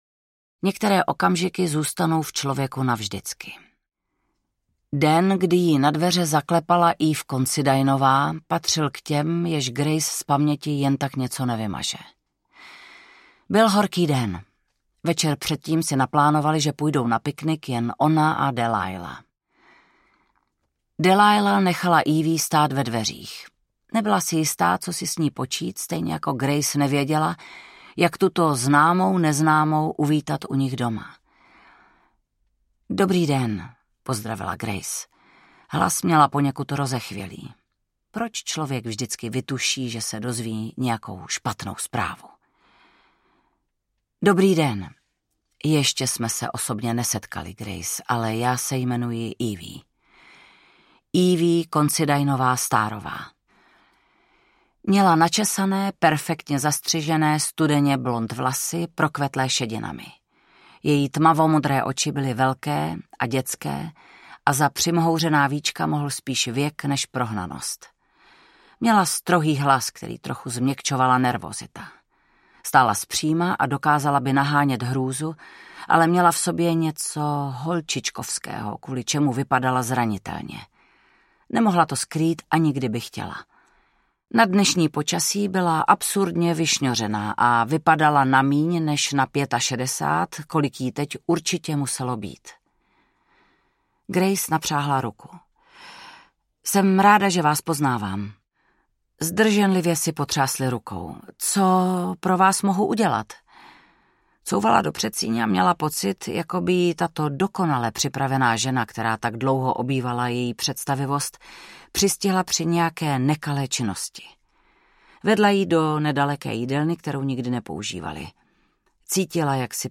Audiobook
Read: Vanda Hybnerová